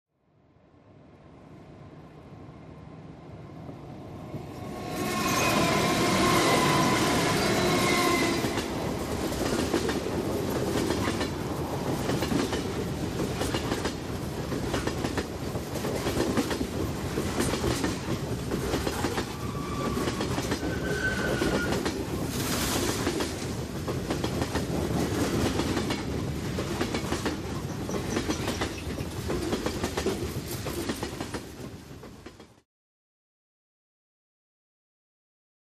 Stop, Train | Sneak On The Lot
Train By With Rail Clicks And Occasional Brake Squeal